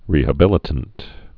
(rēhə-bĭlĭ-tənt)